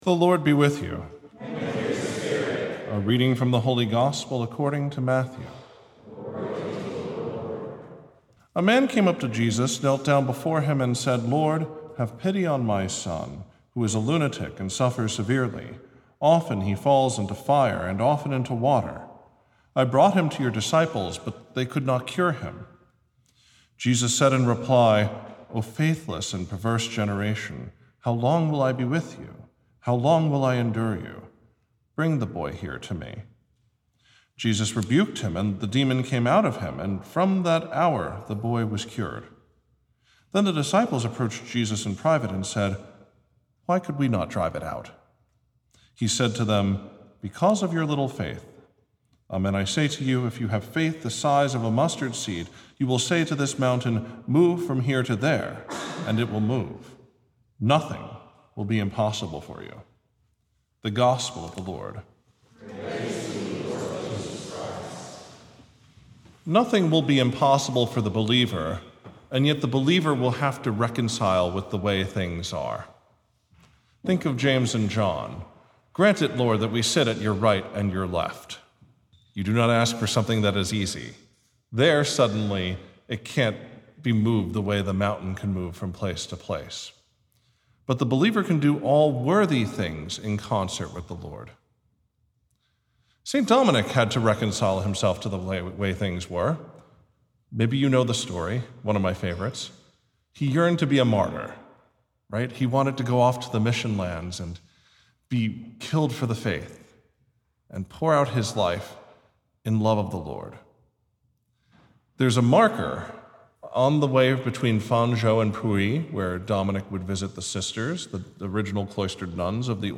03-Saturday-Homily.mp3